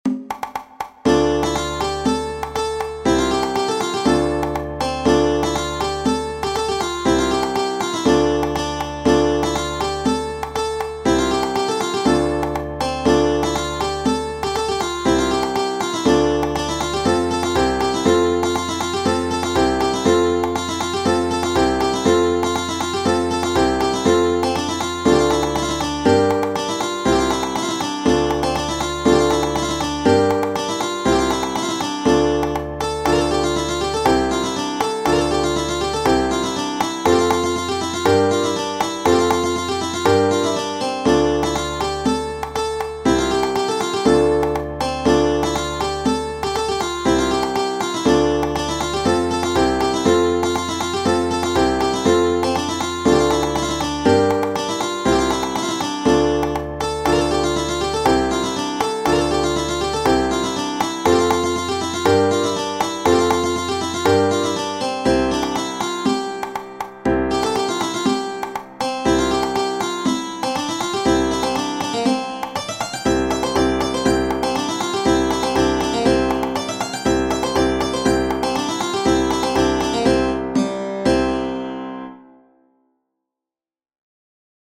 Tradizionale Genere: Folk "Pirgousikos" è un brano musicale e un ballo originario del comune di Pirgi nell'isola di Chios (Grecia). Viene tradizionalmente eseguito durante il Carnevale e nelle feste di matrimonio.